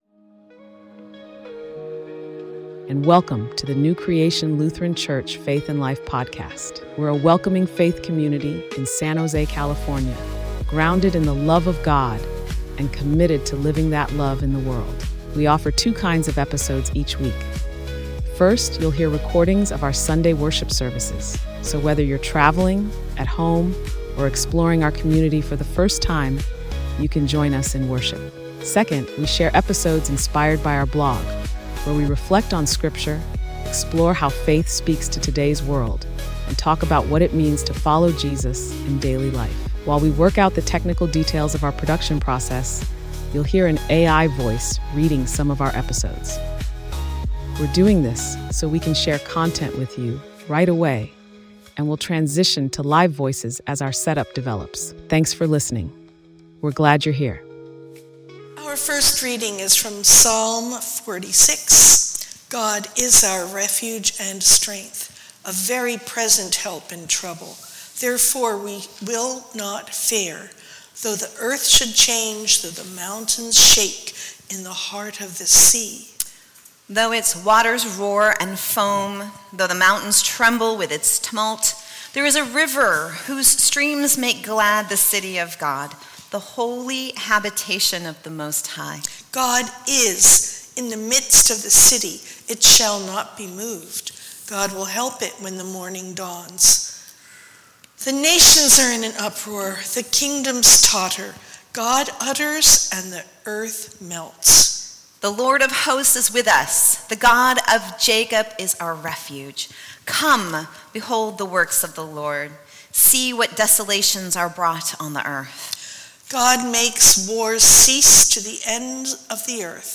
Sermon: Peace in the Midst of the Storm